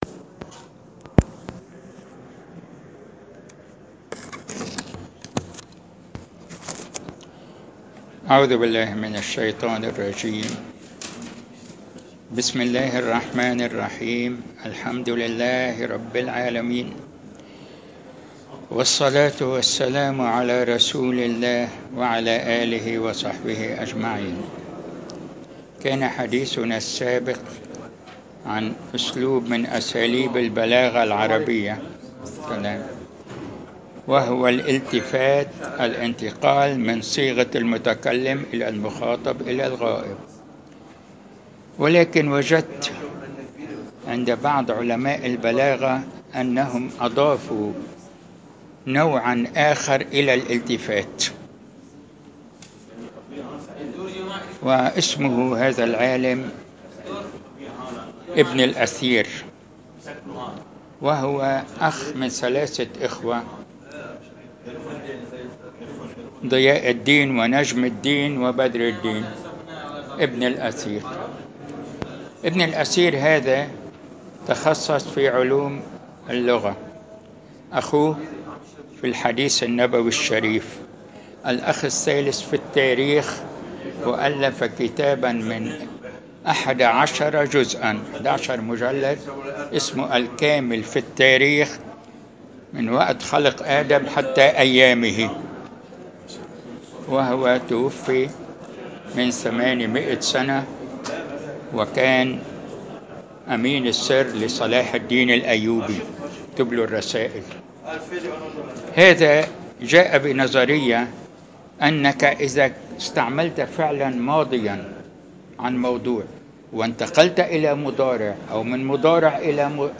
المكان : المسجد البحري الموضوع : إتمام درس الإلتفات